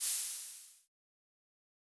steam hisses - Marker #5.wav